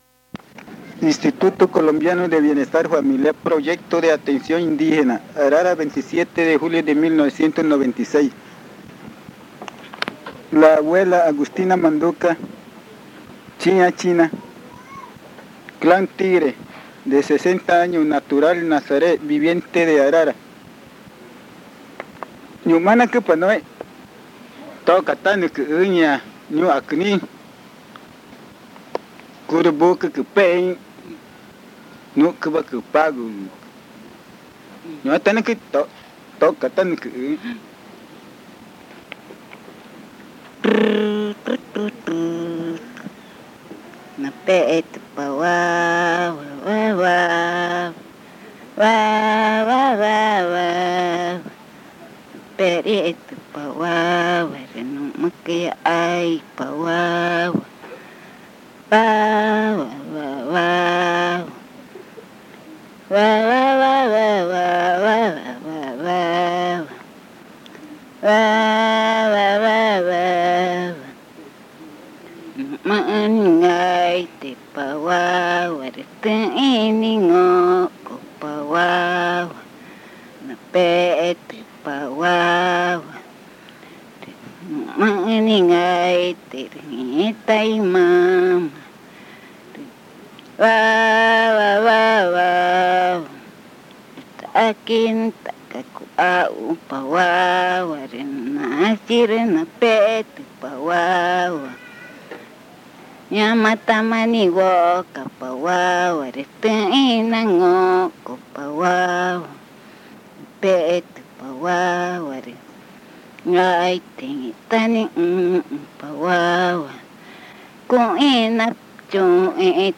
Lullaby 3
Comunidad Indígena Arara
Arrullo cantado por la abuela
Lullaby